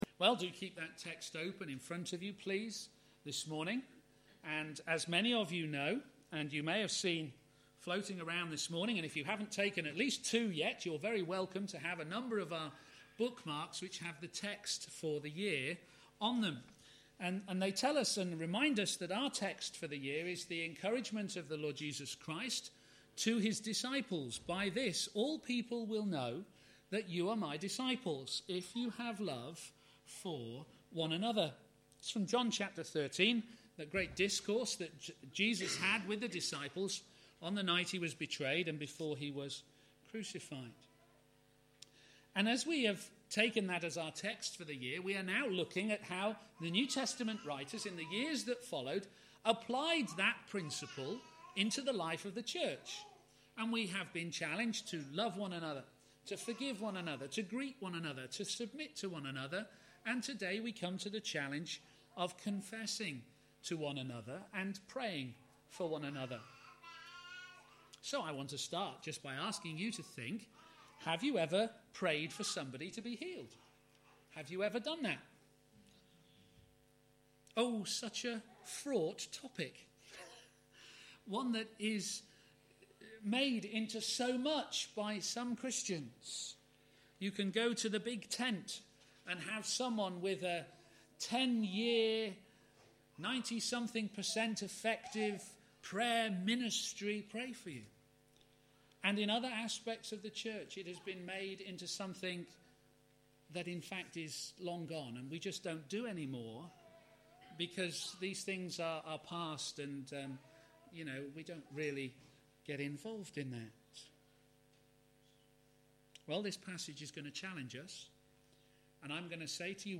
Media Library Media for a.m. Service on Sun 02nd Feb 2014 10:30 Speaker
Theme: Confess to one another Sermon In the search box below, you can search for recordings of past sermons.